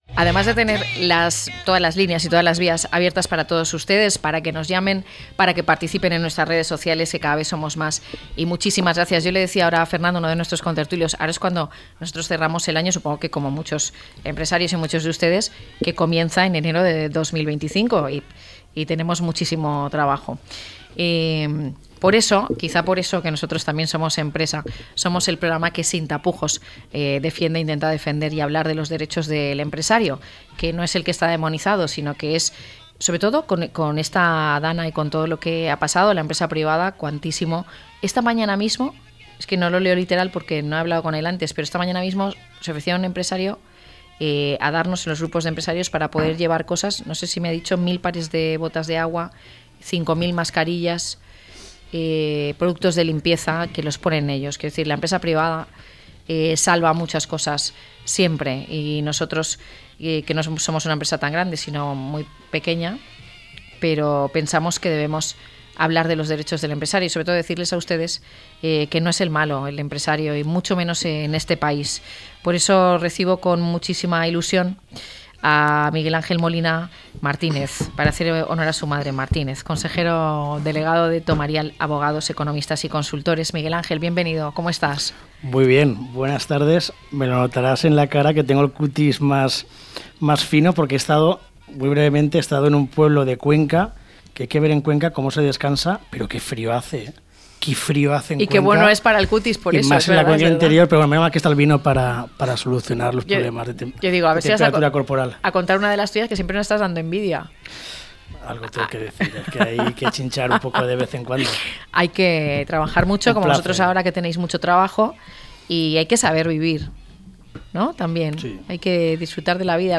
1209-LTCM-TERTULIA.mp3